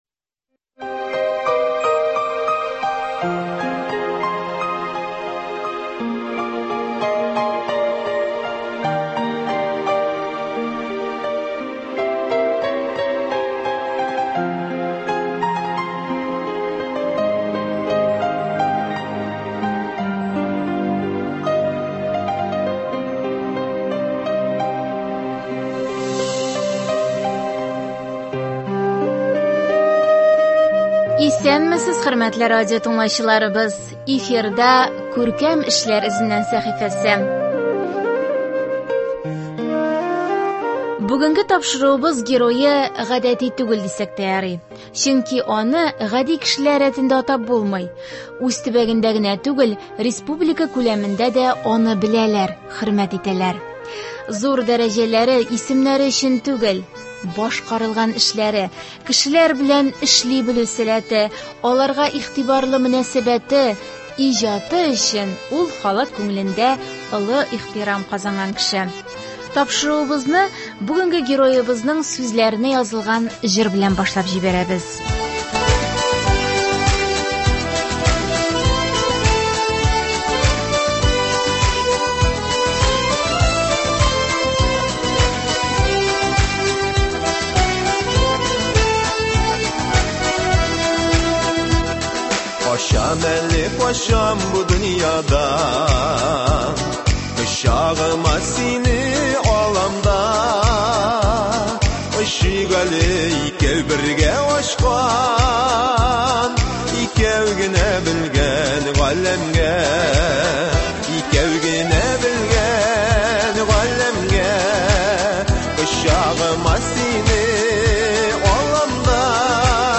әңгәмә тәкъдим итәбез.